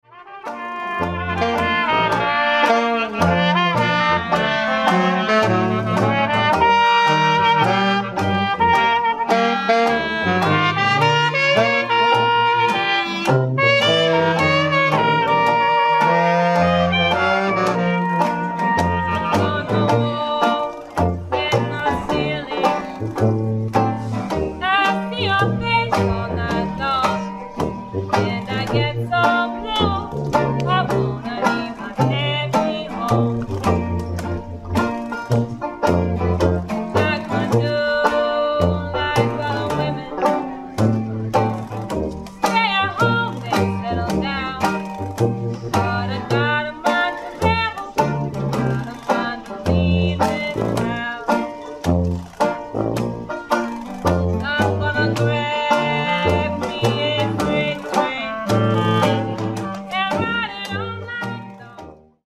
Dixieland-Band